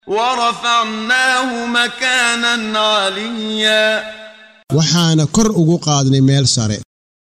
Waa Akhrin Codeed Af Soomaali ah ee Macaanida Suuradda Maryam oo u kala Qaybsan Aayado ahaan ayna la Socoto Akhrinta Qaariga Sheekh Muxammad Siddiiq Al-Manshaawi.